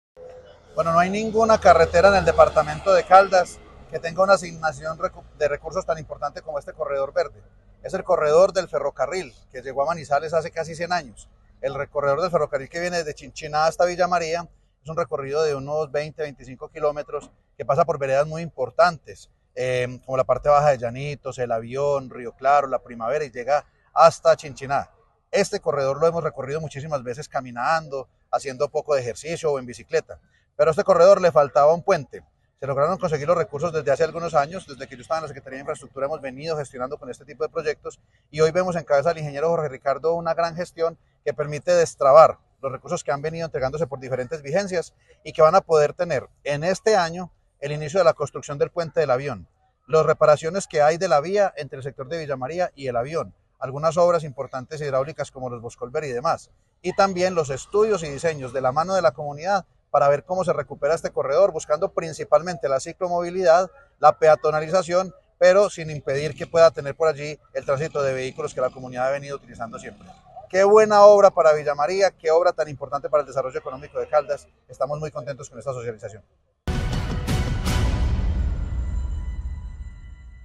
Diputado Luis Alberto Giraldo.